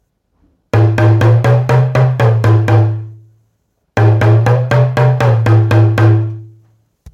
新型 トーキングドラム タマン タムタム アフリカ民族楽器 （p675-27） - アフリカ雑貨店 アフロモード
脇に挟んで付属の湾曲した棒で叩いて演奏します。
脇でテンションを調節することで音を変幻自在に操ることができます。太鼓とは思えないほど豊かな音色を出します。
説明 この楽器のサンプル音 原産国 材質 木、革、ナイロンロープ サイズ サイズ：幅：21cm（ヘッド部：19cm） 長さ：50cm 重量 2.1kg コメント 写真のスティック付。